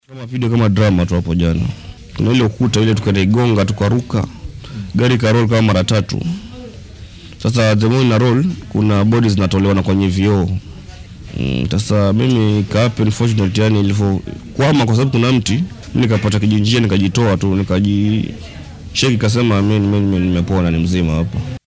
Mid ka mid ah dadka dhaawacmay ayaa ka warramaya sida uu shilka u dhacay.